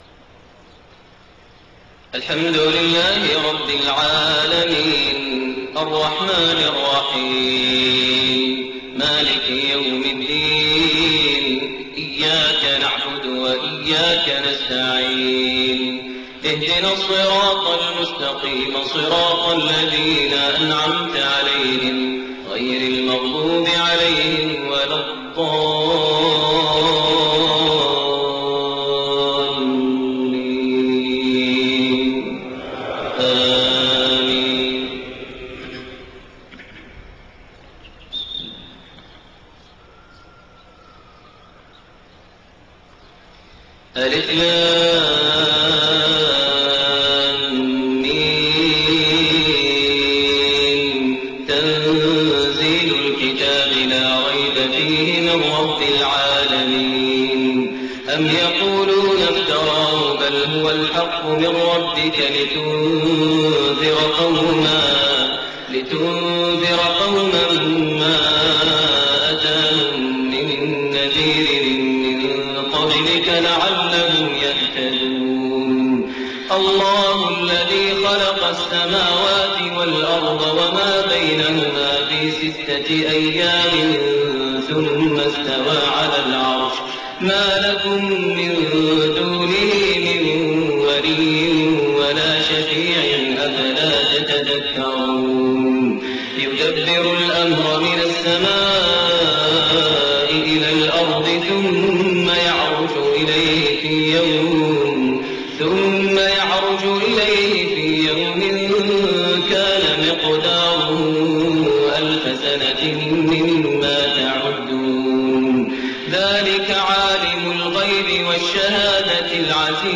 Fajr prayer from Surah As-Sajda and Al-Insaan > 1429 H > Prayers - Maher Almuaiqly Recitations